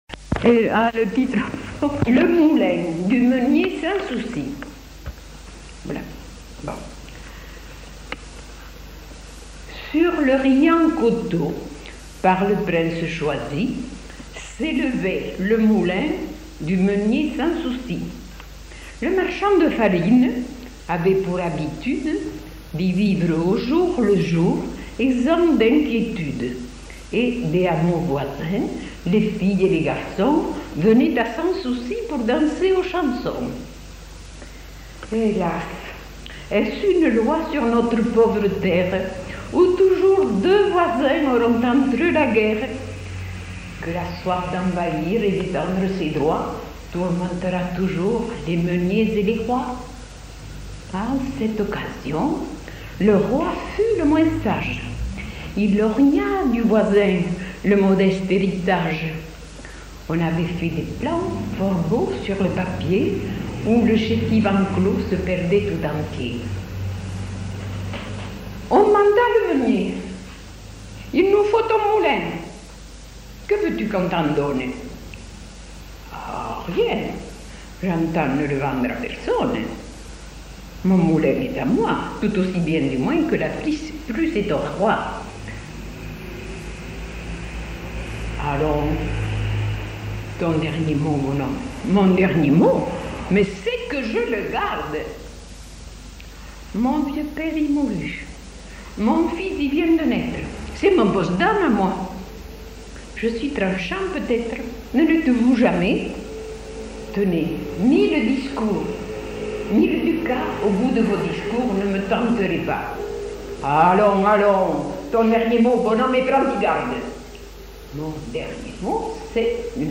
Aire culturelle : Haut-Agenais
Lieu : Cancon
Genre : poésie
Effectif : 1
Type de voix : voix de femme
Production du son : récité